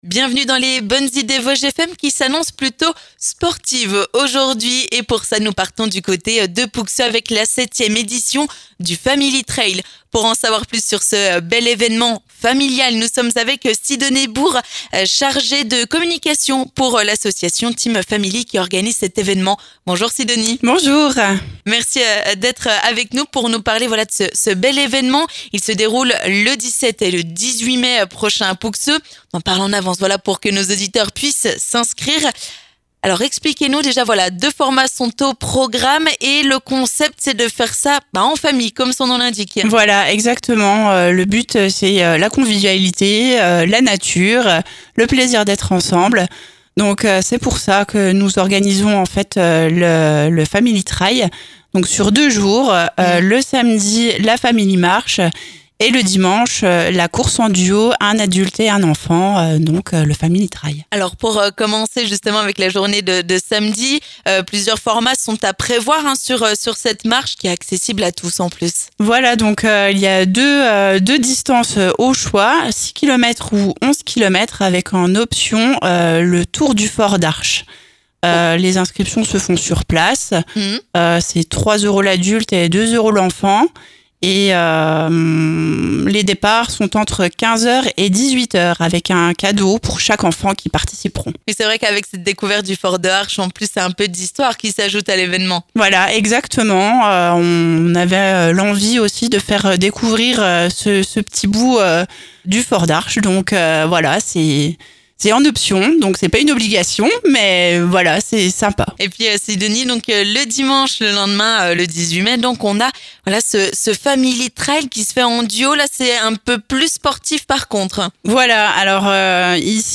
Le Family Trail revient les 17 et 18 mai à Pouxeux pour une 7ème édition ! Au programme samedi, plusieurs parcours de marche, et dimanche, les fameuses courses à faire en duo : un adulte et un enfant ! Pour en savoir plus sur ce bel événement familial, nous accueillons en studio